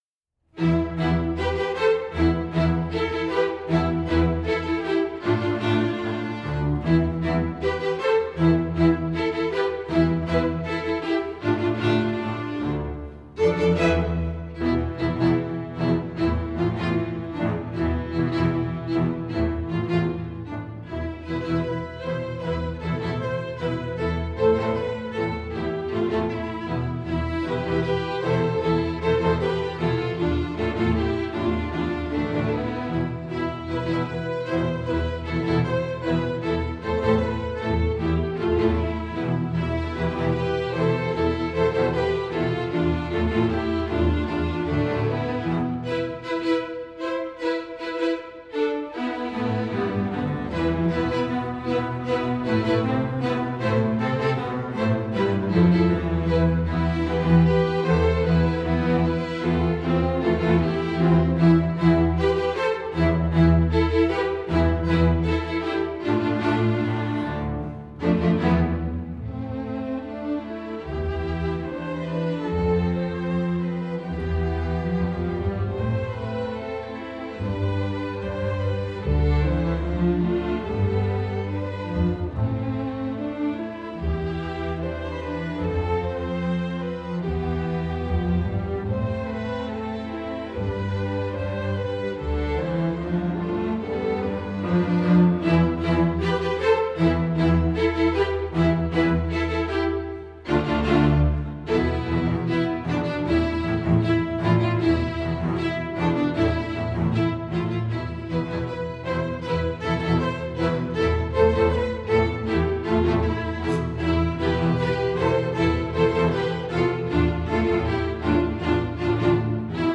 Voicing: Strg Orch